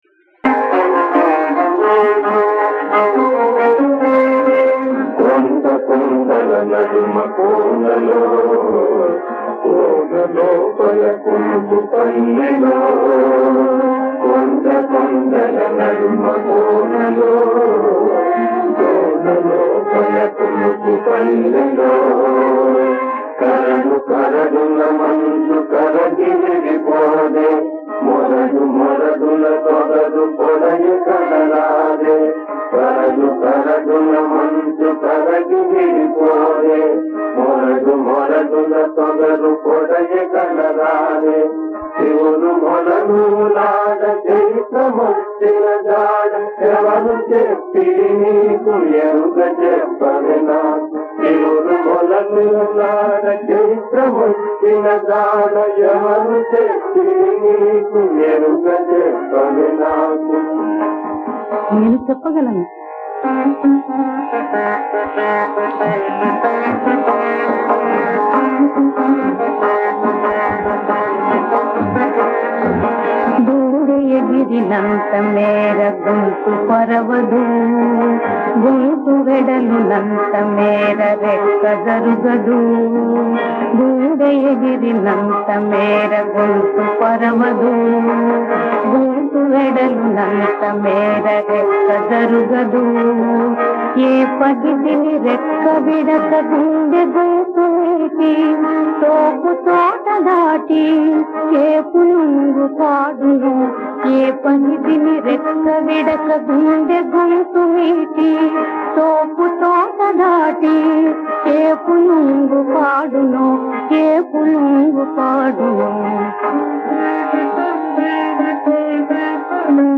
కొండ కోనల, కొలను కొలనుల – బృందగానం